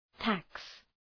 Προφορά
{tæks}